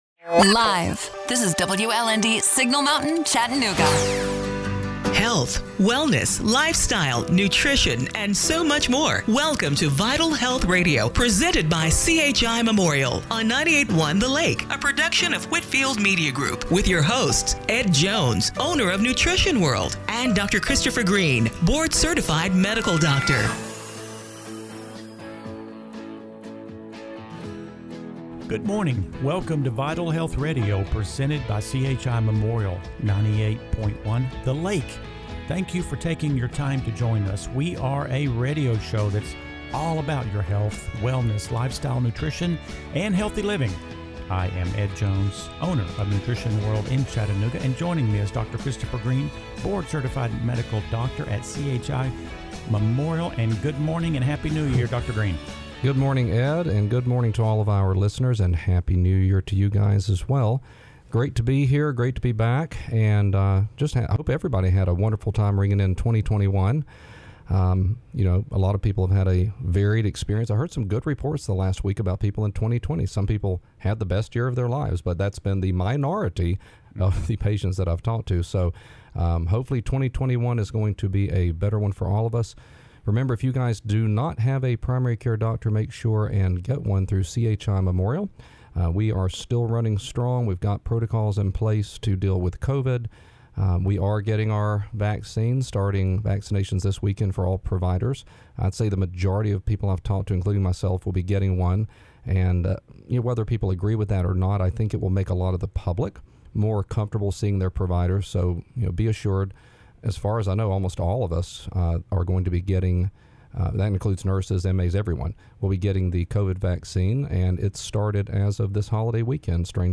January 3, 2021 – Radio Show - Vital Health Radio